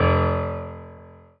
piano-ff-09.wav